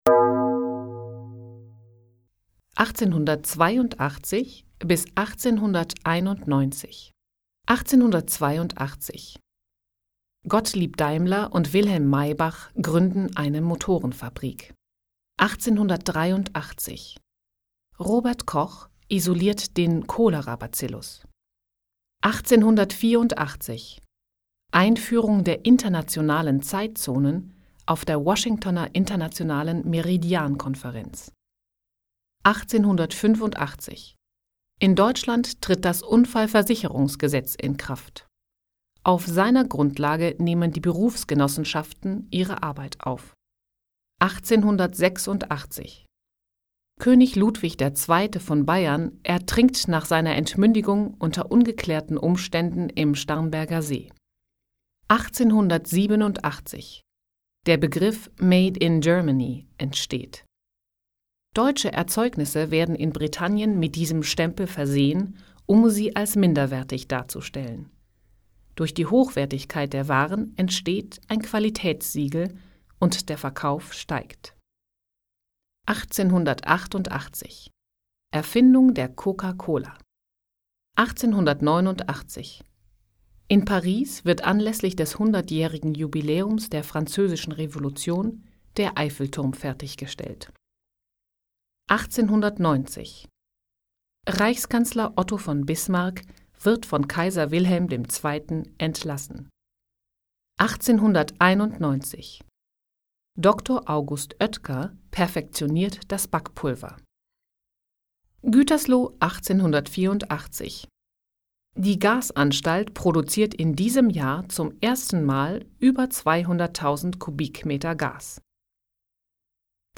Hier findest du eine Auswahl verschiedener Stimmfarben und Sprachen, die ich bedienen kann: